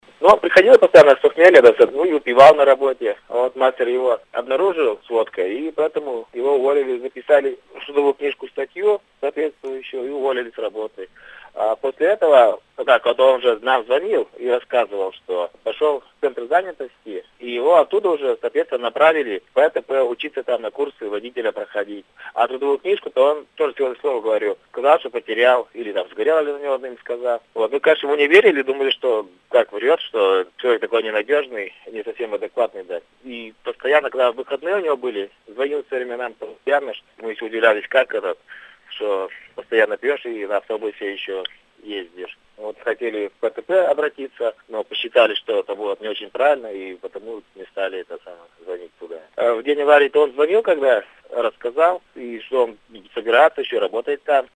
Бывший коллега рассказывает о виновнике аварии